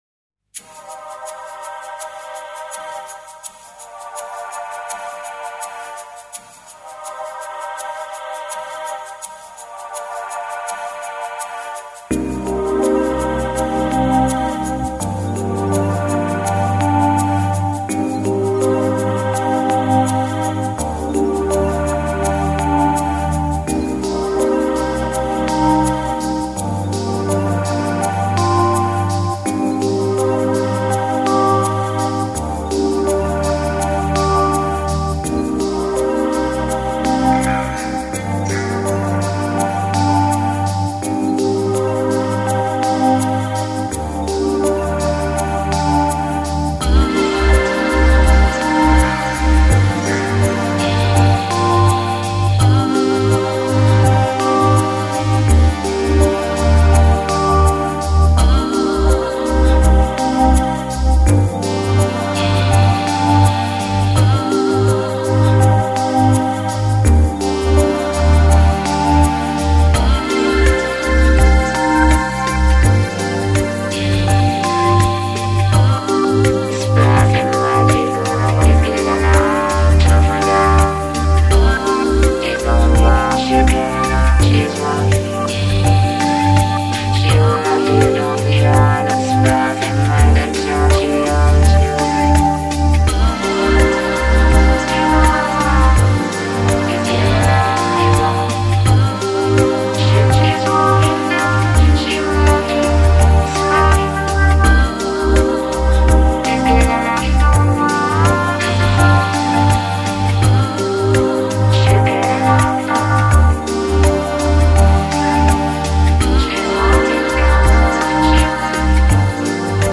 Largo [0-10] amour - ensemble instruments - - -